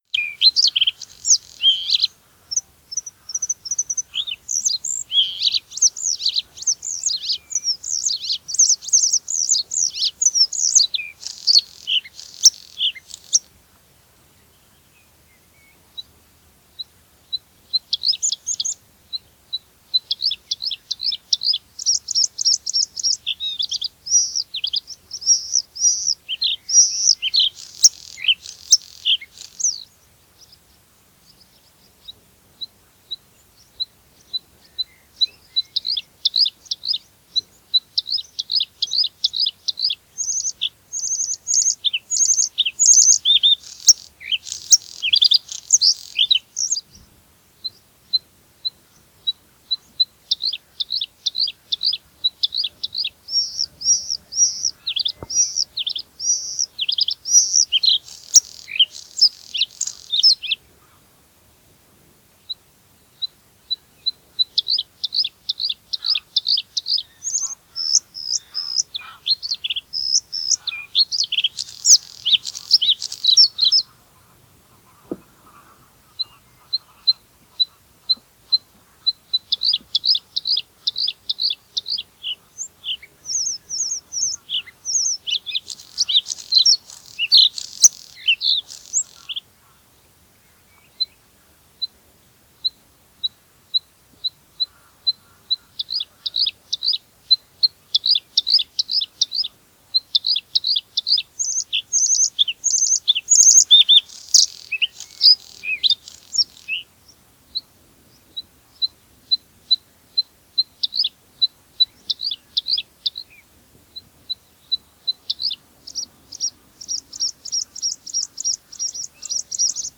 blauwborst
🔭 Wetenschappelijk: Luscinia svecica cyanecula
♫ zang filmpje 2014
blauwborst_zang.mp3